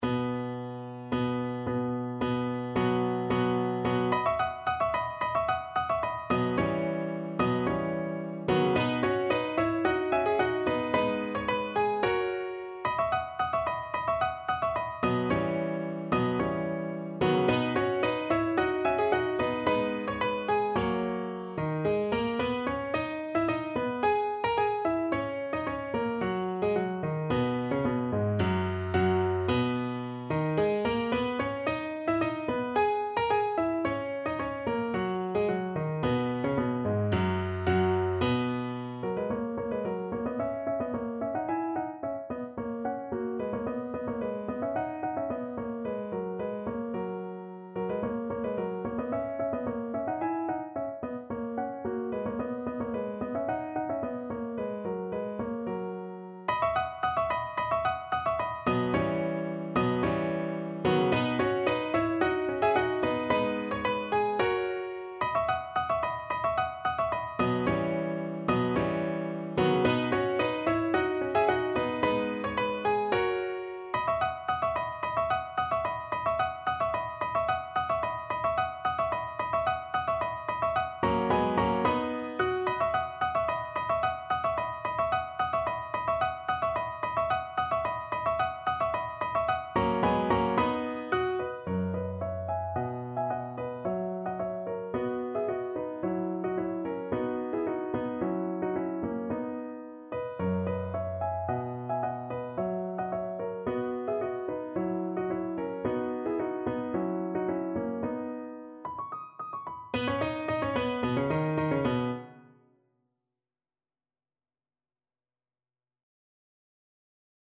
Piano version
No parts available for this pieces as it is for solo piano.
= 110 Allegro di molto (View more music marked Allegro)
2/2 (View more 2/2 Music)
Piano  (View more Advanced Piano Music)
Classical (View more Classical Piano Music)